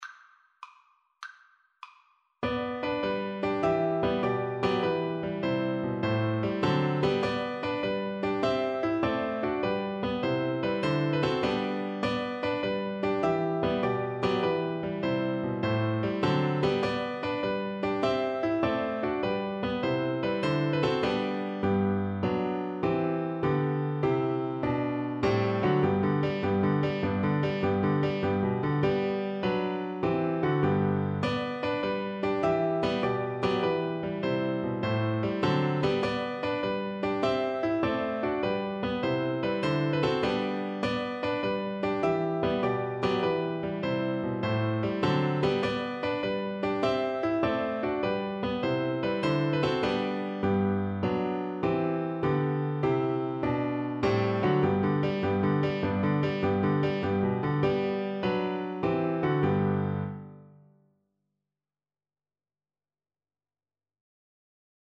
6/8 (View more 6/8 Music)
~ = 150 A1 Joyfully
D6-G7